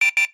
Enemy SFX
alarm Mixdown 6.mp3